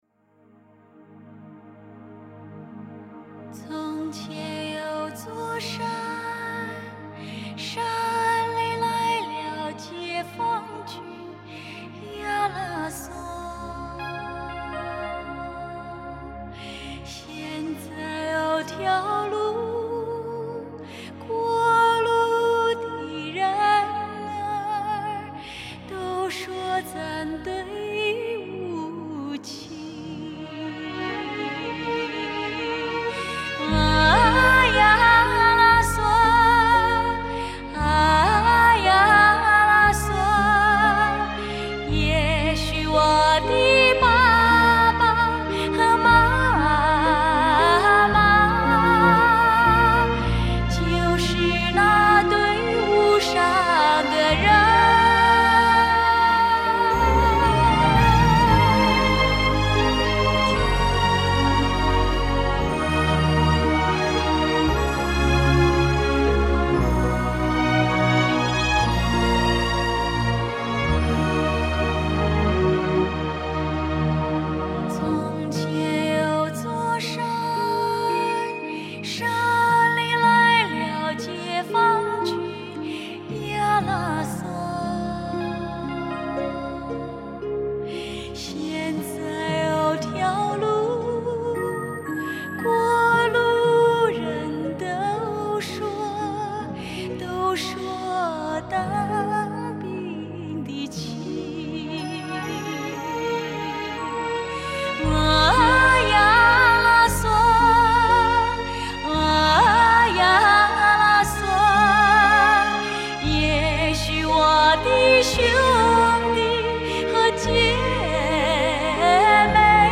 她的歌声高昂激越、委婉深沉、风趣诙谐，还透着质朴，犹如她的为人。